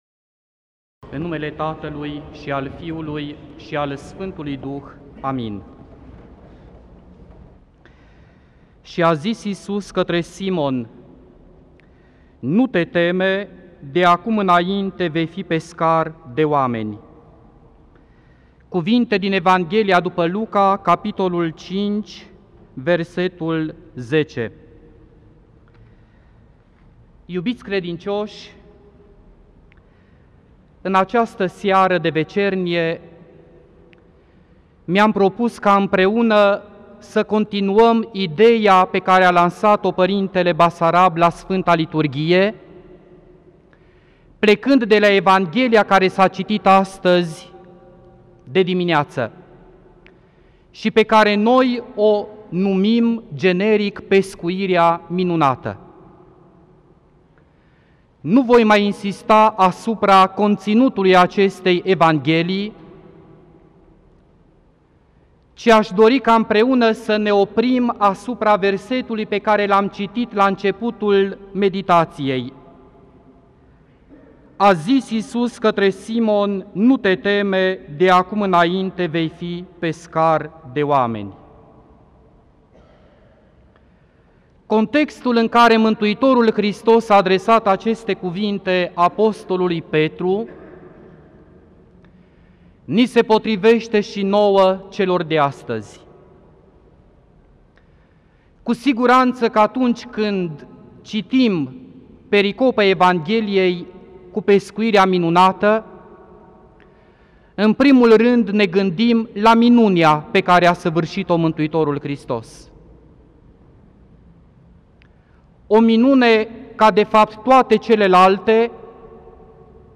Predică la Duminica a 18-a după Rusalii
Cuvinte de învățătură Predică la Duminica a 18-a după Rusalii